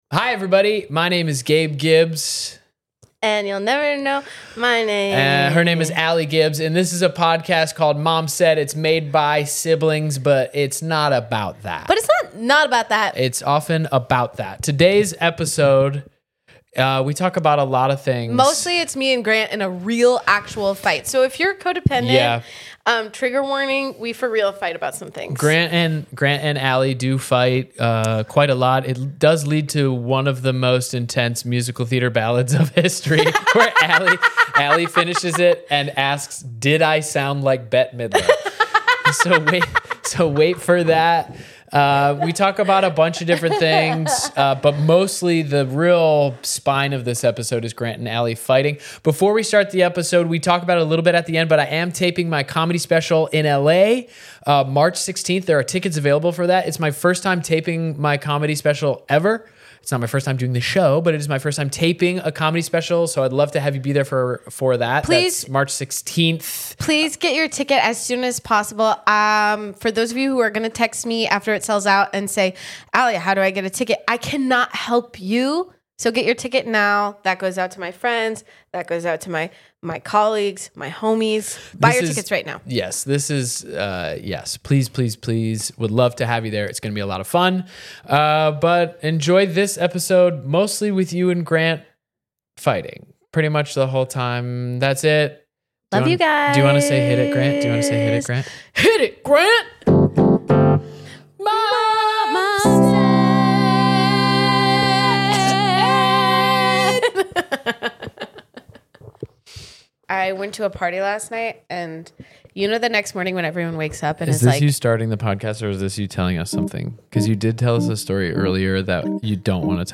It’s episode 8 and we are already sing-battling our real feelings.